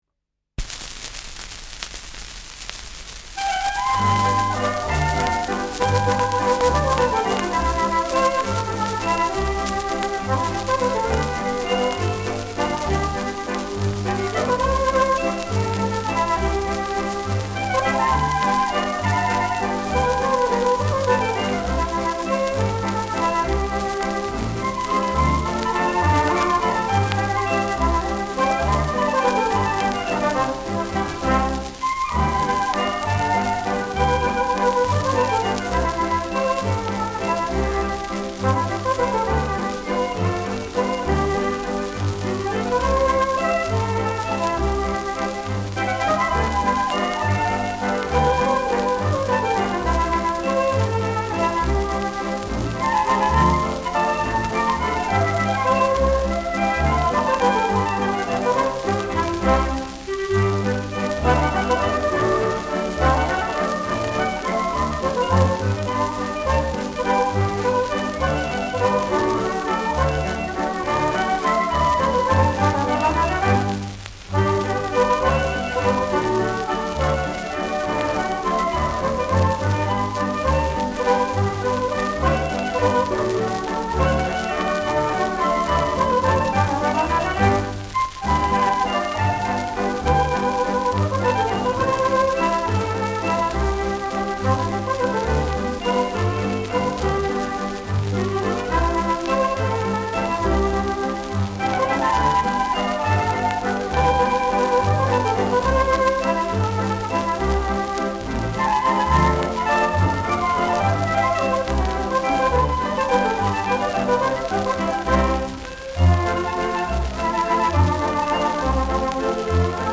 Schellackplatte
leichtes Knistern
Ländlerkapelle* FVS-00018